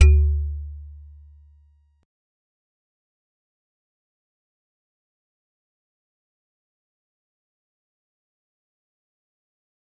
G_Musicbox-D2-pp.wav